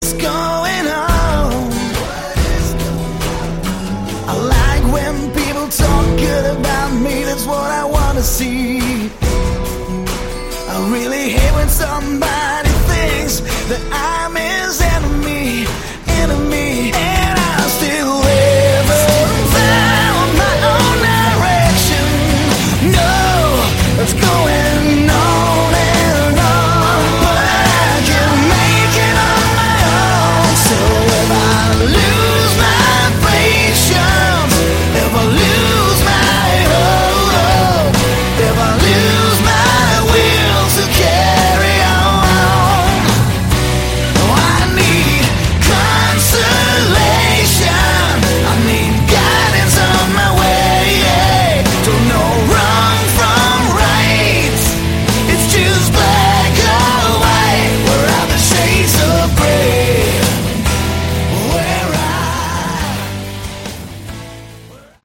Category: Melodic Hard Rock
guitars, bass, programming
keyboards